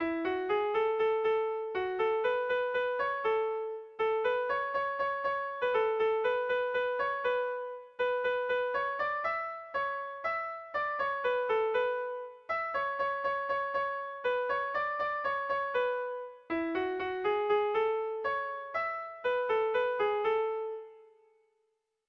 Kontakizunezkoa
Hamarreko txikia (hg) / Bost puntuko txikia (ip)
A-B-C-D-E